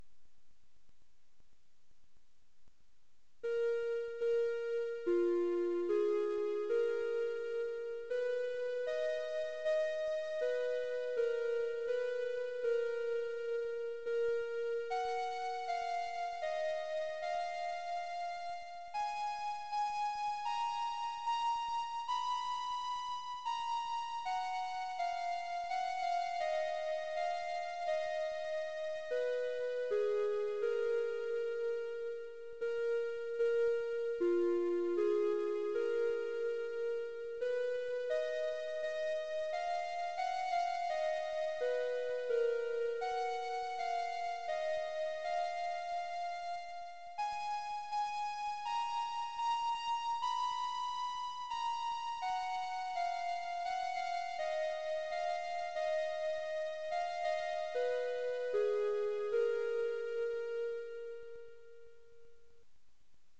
演奏2 mp3